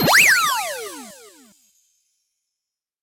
Checkpoint sound effect from Super Mario 3D World.